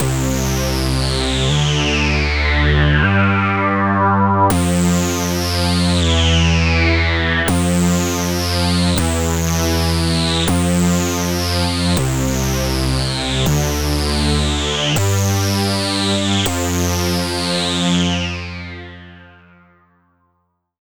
Track 16 - Deep Synth.wav